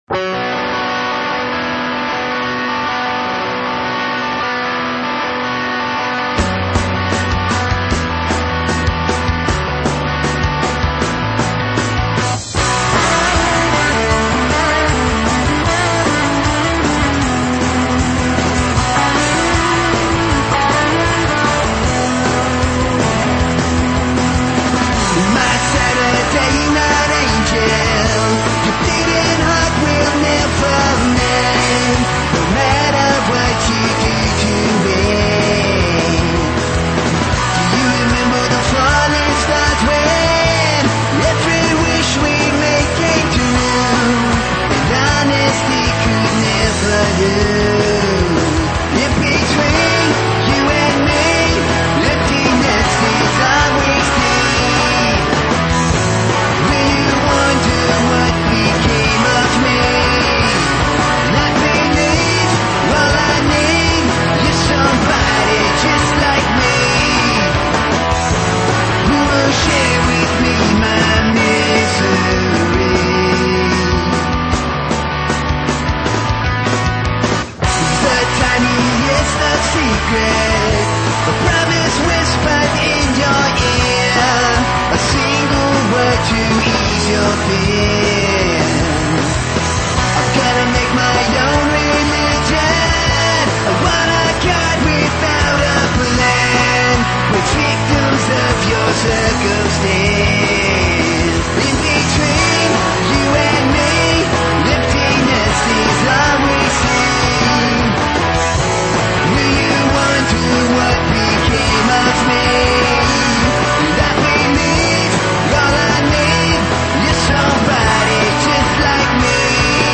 punk
rock
metal
high energy rock and roll